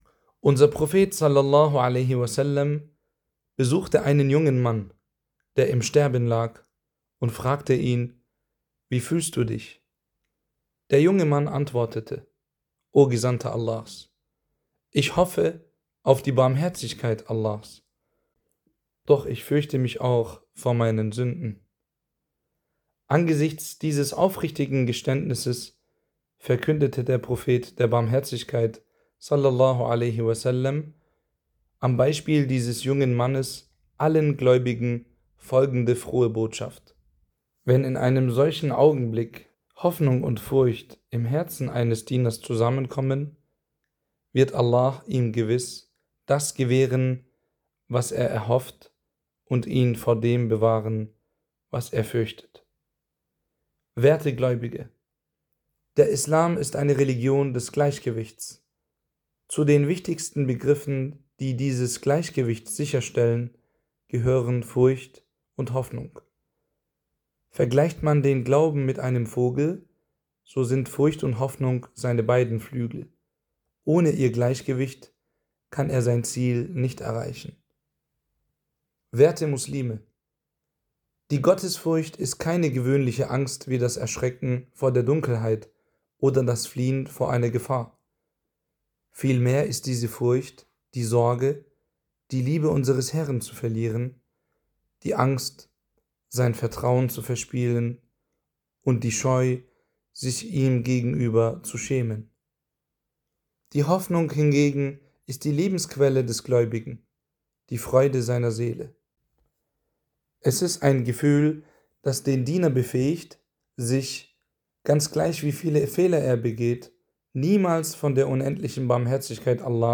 Freitagspredigt